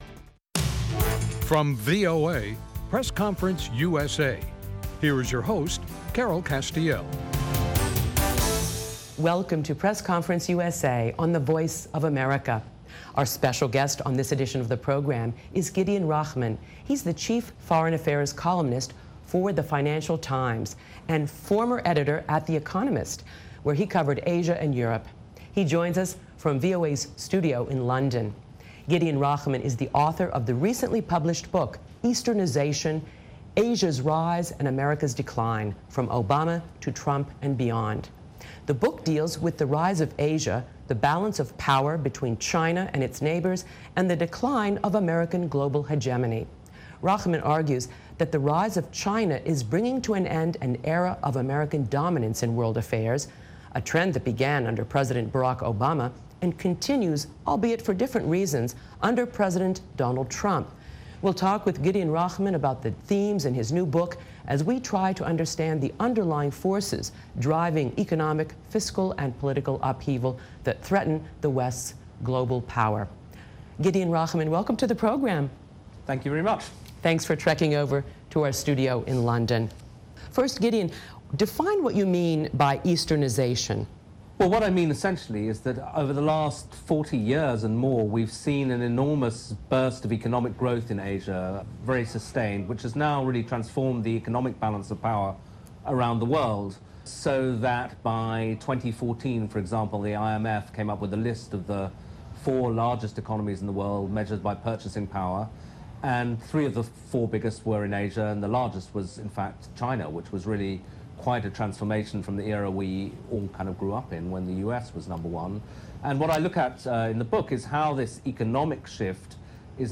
From VOA’s London bureau